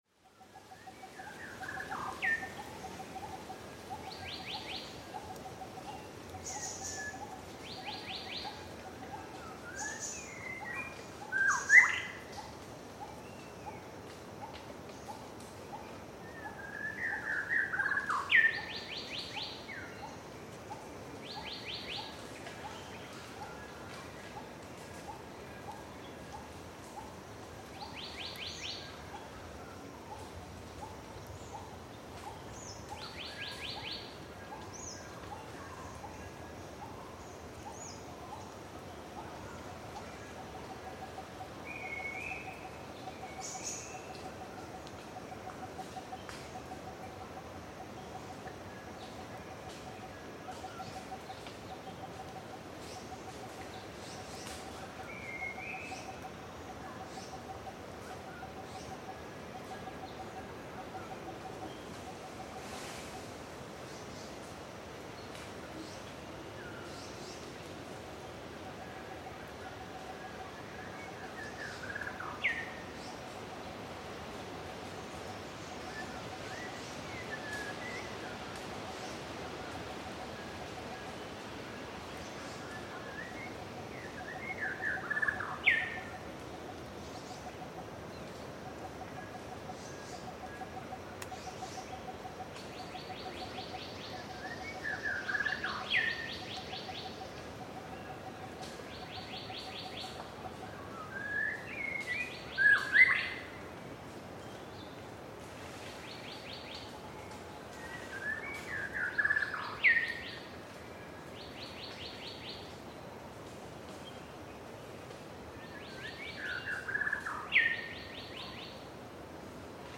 Dry zone jungles
Our selected site is free of man-made noises most of the time, and rich in the sounds of birds, some mammals and many insects.
Morning Soundscapes
(Rode NT4 XY stereo on Marantz PMD671)
(Note: wind sound in background is heavy due to monsoon winds in June)
Sigiriya-2_Rode-XY.mp3